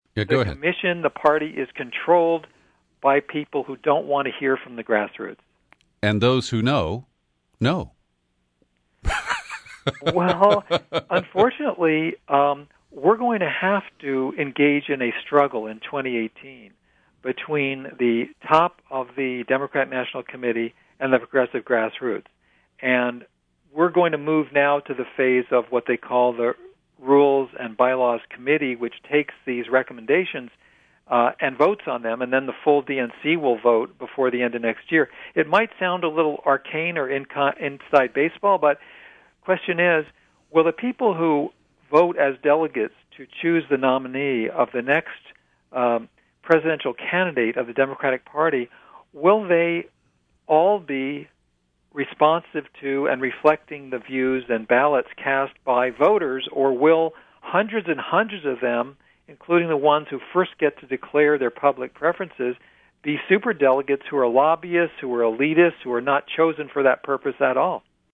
In-Depth Interview: Norman Solomon Reports on Democrats’ Unity Reform Commission